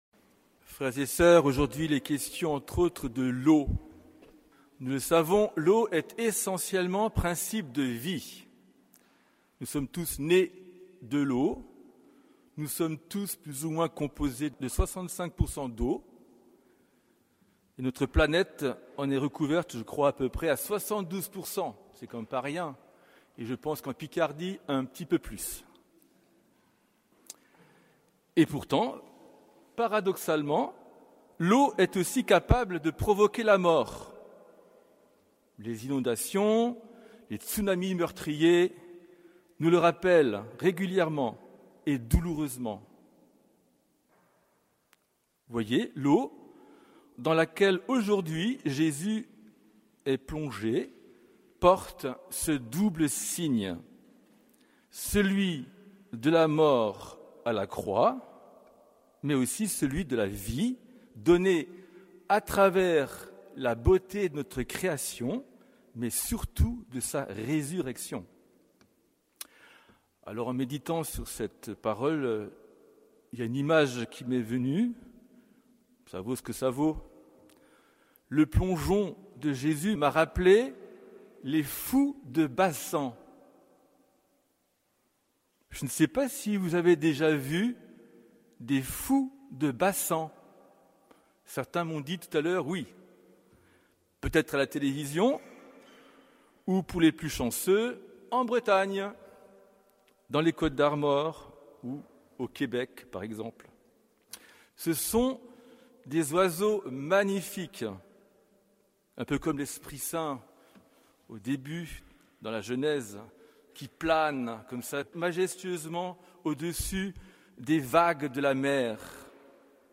Homélie de la fête du baptême du Seigneur